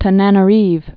(tə-nănə-rēv, tä-nä-nä-rēv)